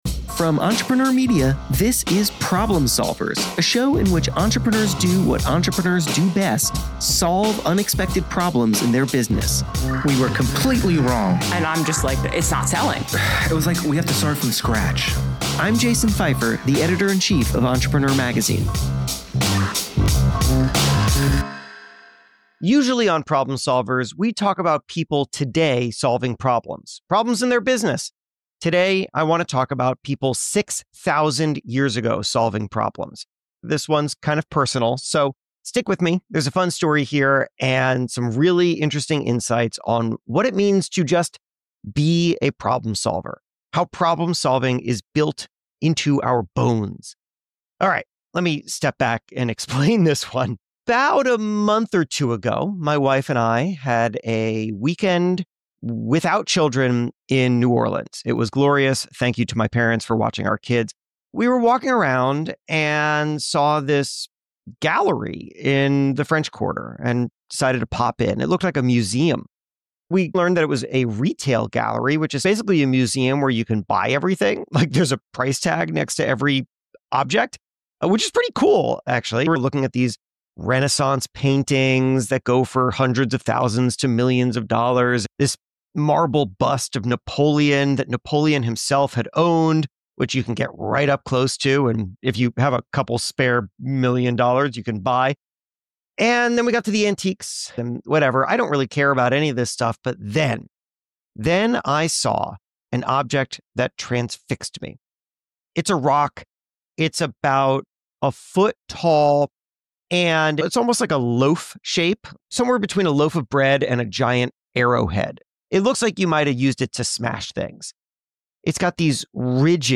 for a conversation about the original problem-solvers. They explore how communication became humanity’s superpower, why the stone ax was the first handheld all-purpose tool (and how it’s surprisingly similar to a smartphone), and the ancient problem that shelves were designed to solve.